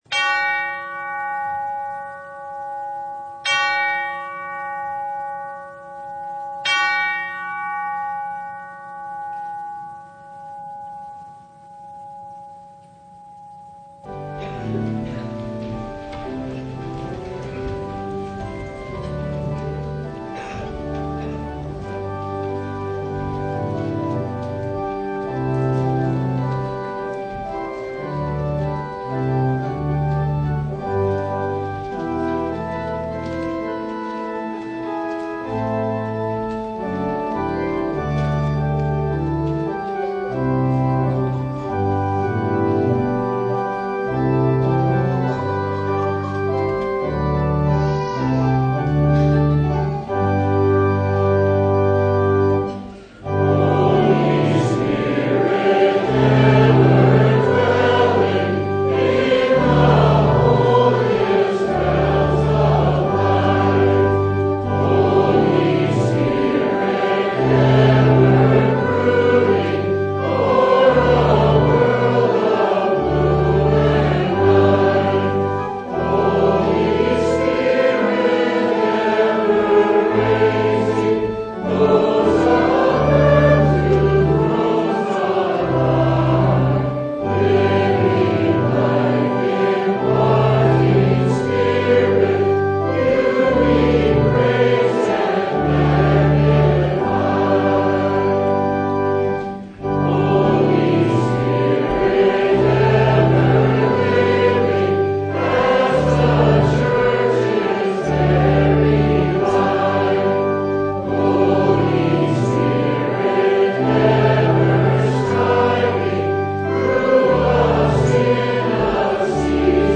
Matthew 5:21-37 Service Type: Sunday Jesus has anger